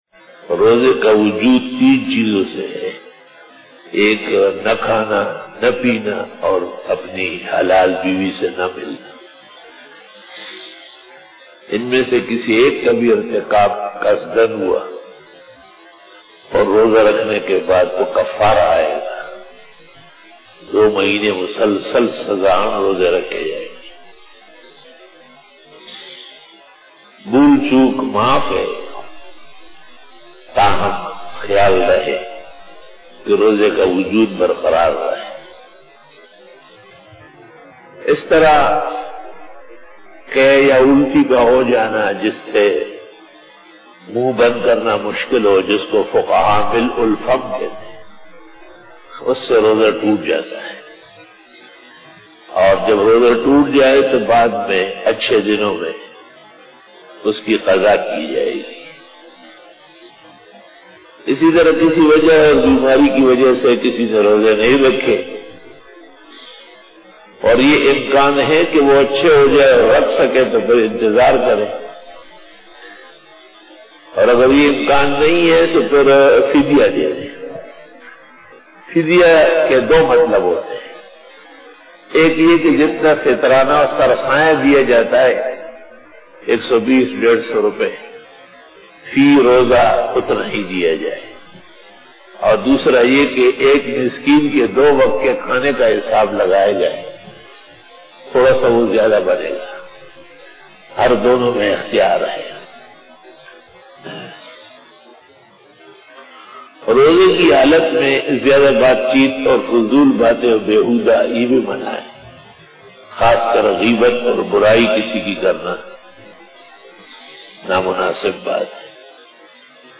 After Namaz Bayan
بیان بعد نماز فجر